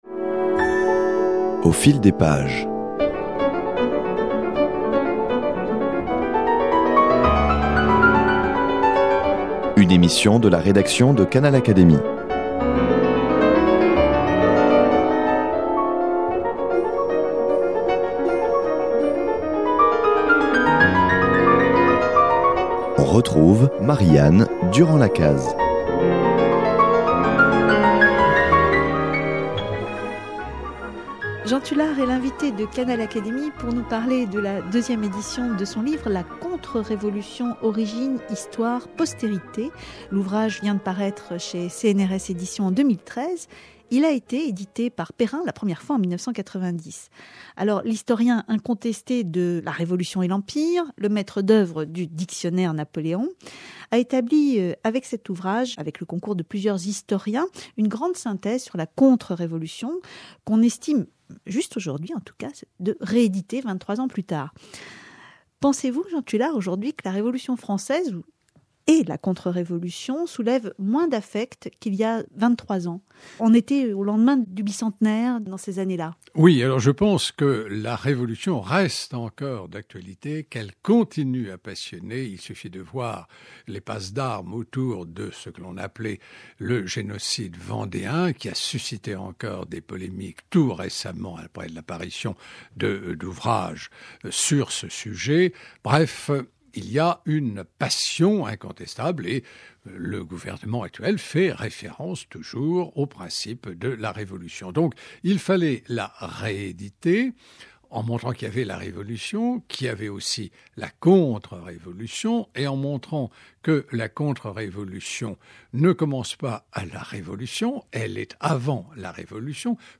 Jean Tulard est l’invité de Canal Académie pour nous parler de la deuxième édition de son livre La Contre-Révolution. Origines Histoire, Postérité.